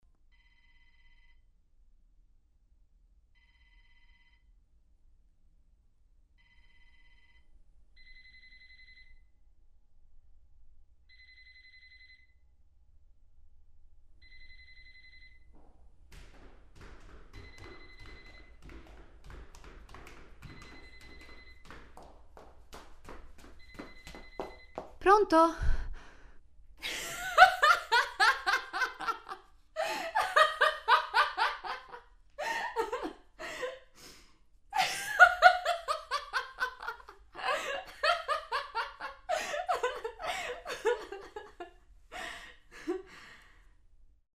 Il consiglio è sempre lo stesso: ASCOLTATE SOLO CON LE CUFFIE altrimenti non potrete cogliere gli effetti spaziali
Telefono
Rispondi al telefono.mp3